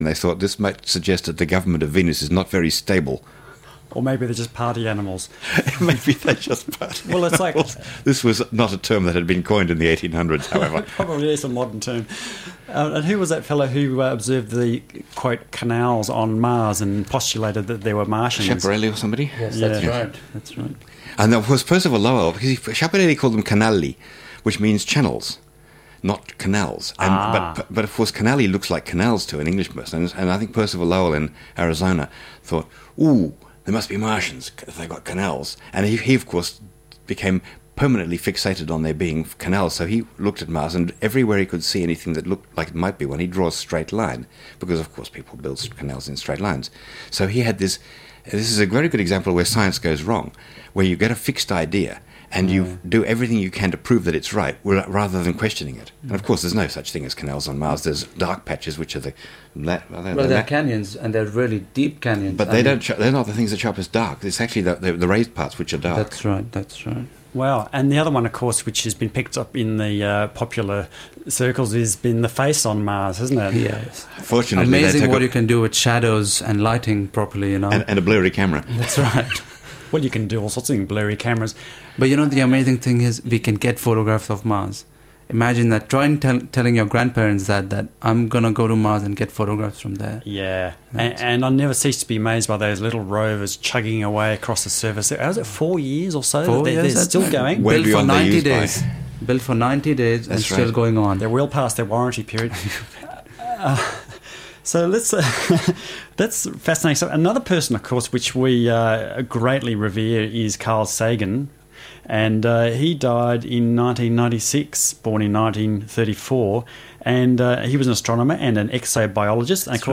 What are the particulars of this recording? Here is the link to the mp3 file (first few minutes missing - as the track starts we were talking about the Ashen Light glow on the dark side of Venus, which was initially attributed to celebrations for a new ruler of the planet.